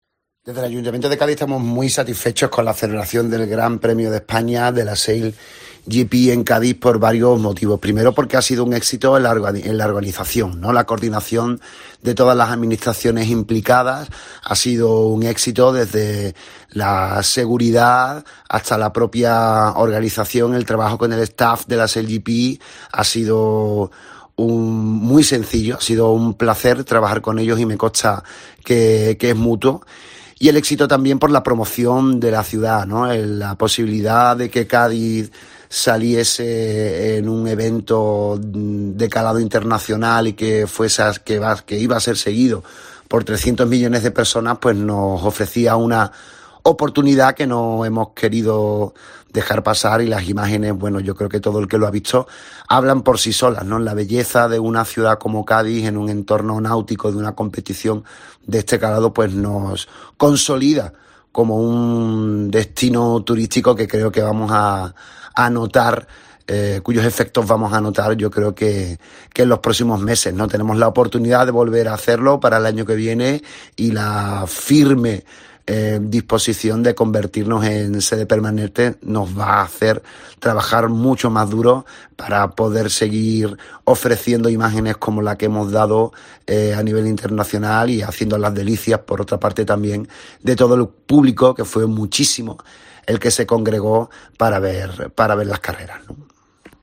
El alcalde de Cádiz valora el Sail GP en la ciudad